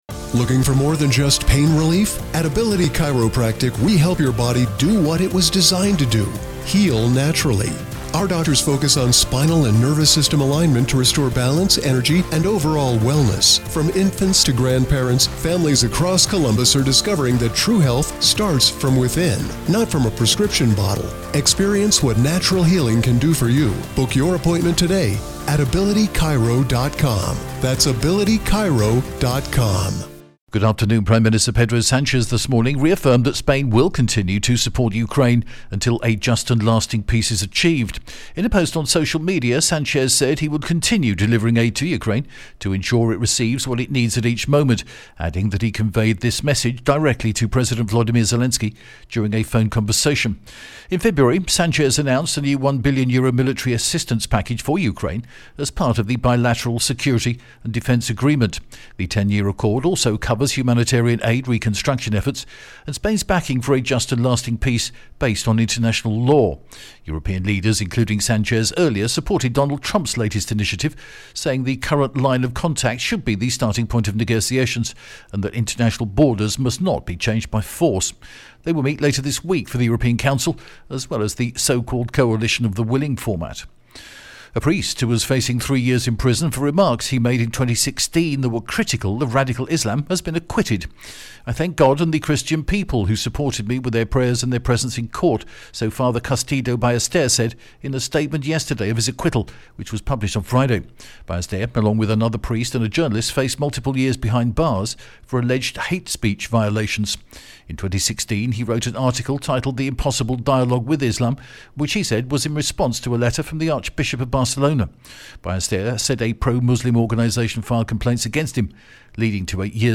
TRE is the only broadcaster in Spain to produce and broadcast, twice daily its own, in house, Spanish and local news service in English...and we offer this to you as a resource right here, and on the hour at tre.radi...